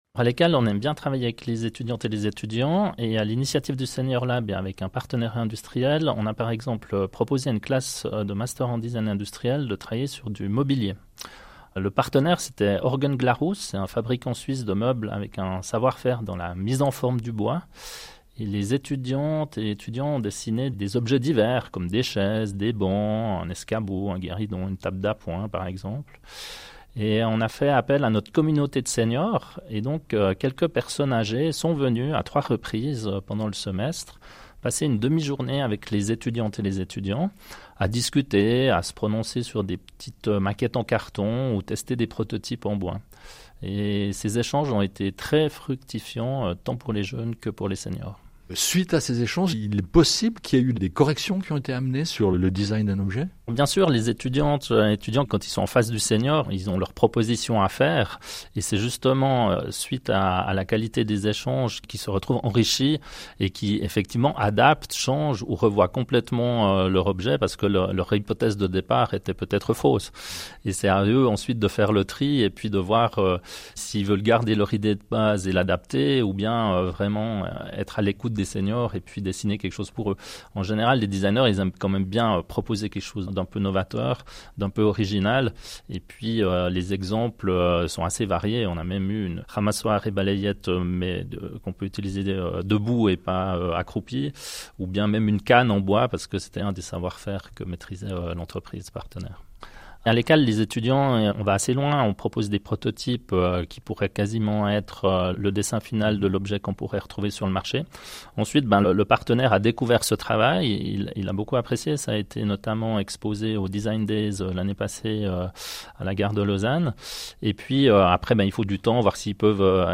Les membres du groupe du pilotage du senior-lab étaient à l’antenne de l’émission CQFD de la RTS en décembre 2024 pour présenter trois projets pilotés par les trois hautes écoles fondatrices de la plateforme.